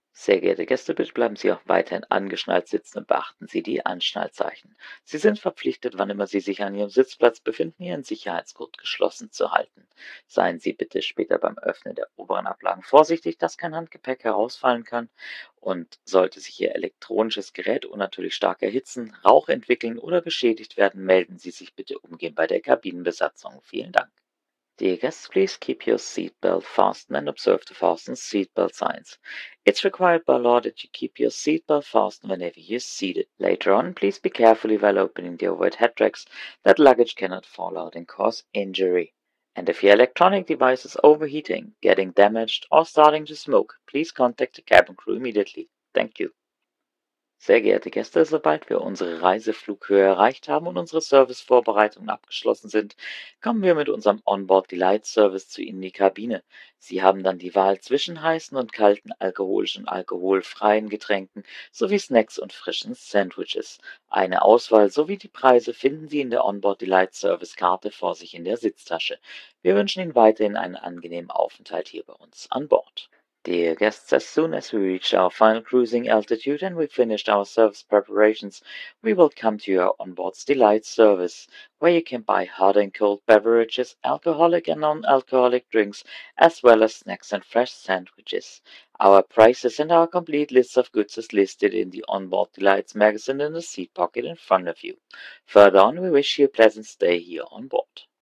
MSFS_Simbrief/Announcements/DLH/AfterTakeoff.ogg at 6f0ff4bc00ecdf92f3e5728fded5a179f67ae3eb
AfterTakeoff.ogg